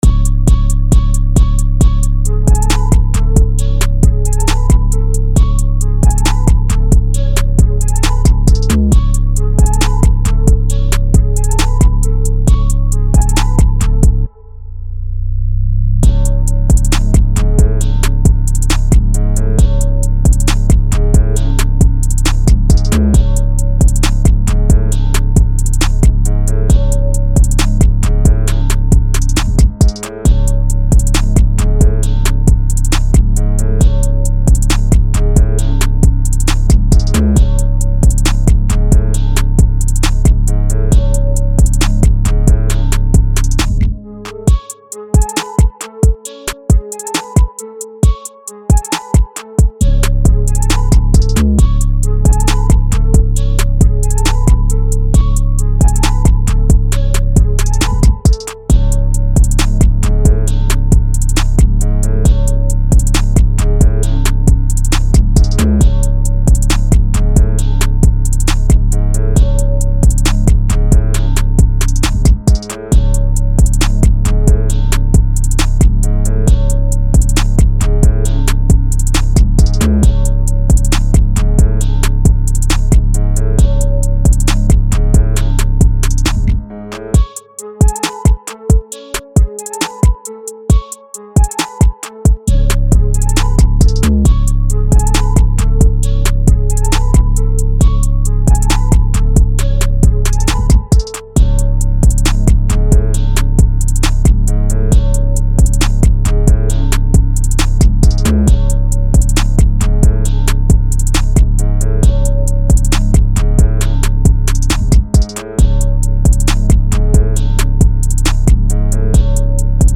Hip Hop
C# Minor